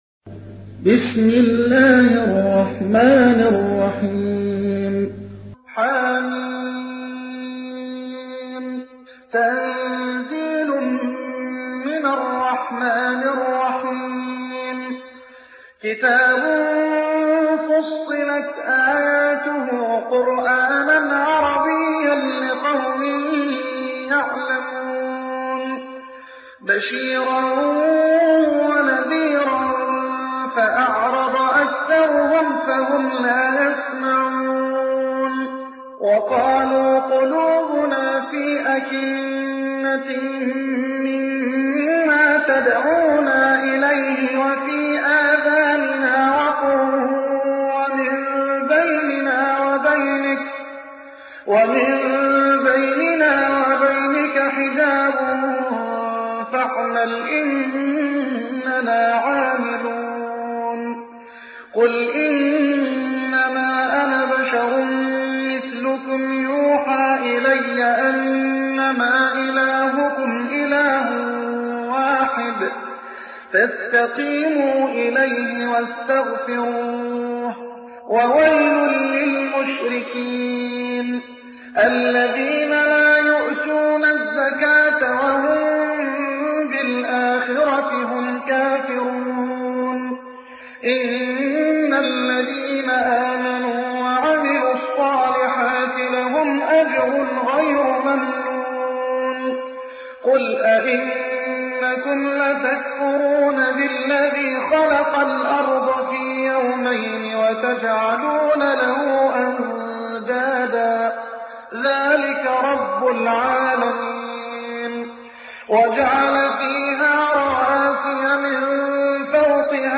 تحميل : 41. سورة فصلت / القارئ محمد حسان / القرآن الكريم / موقع يا حسين